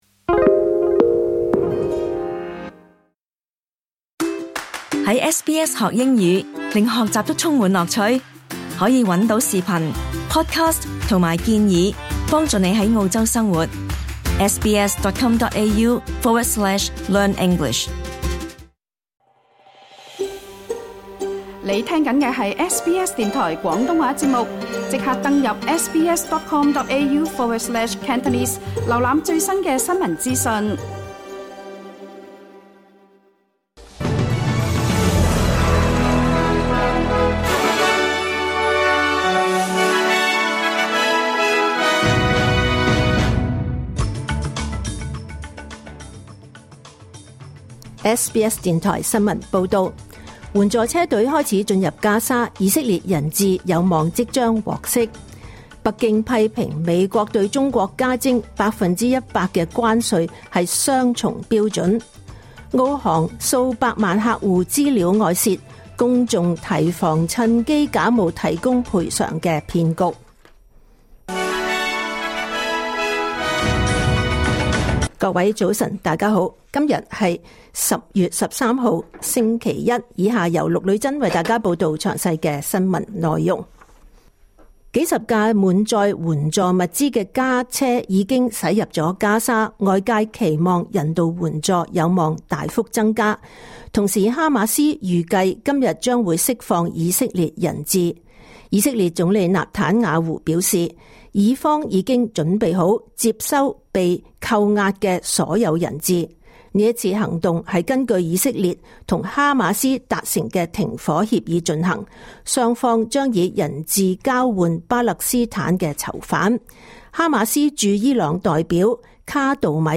2025年10月13日SBS廣東話節目九點半新聞報道。